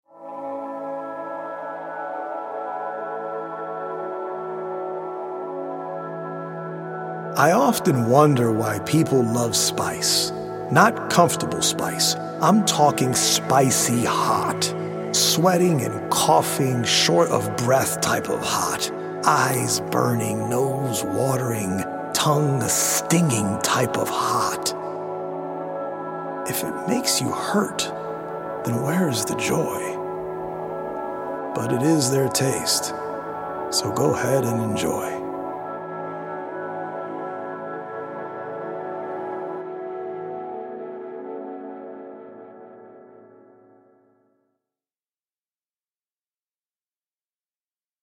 healing audio-visual poetic journey
healing Solfeggio frequency music
EDM producer